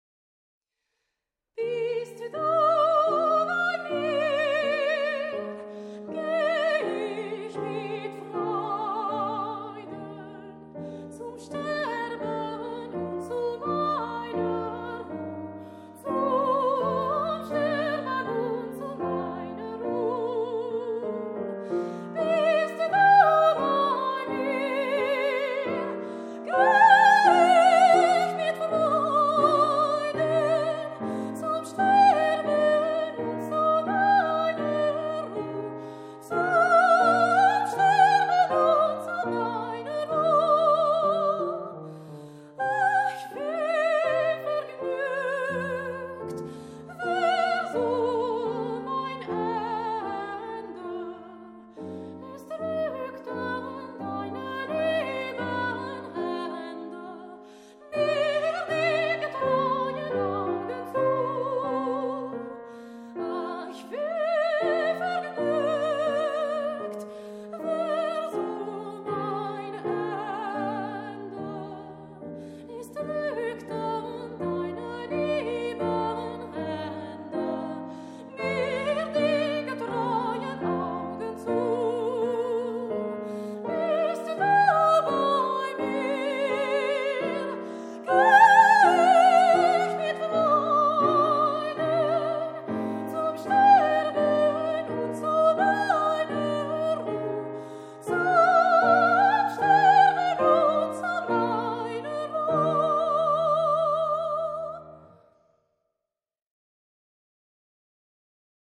Carnets du front : Notes fraternelles pour voix et piano
soprano
piano